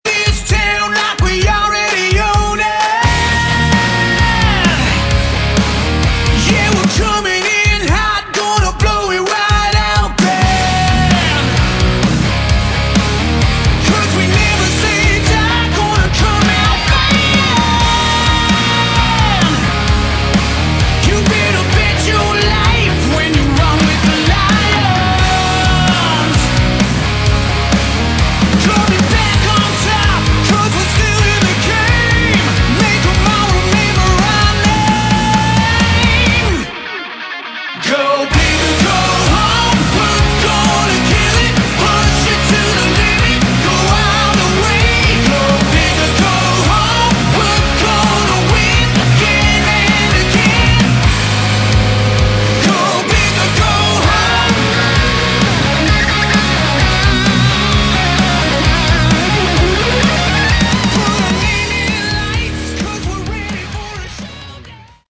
Category: AOR / Melodic Rock
bass
vocals, guitar
drums